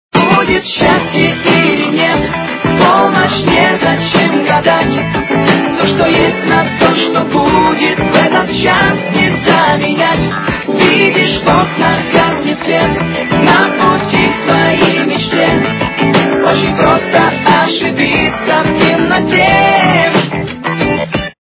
украинская эстрада
качество понижено и присутствуют гудки